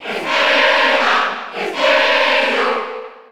Category:Crowd cheers (SSB4) You cannot overwrite this file.
Rosalina_&_Luma_Cheer_Spanish_PAL_SSB4.ogg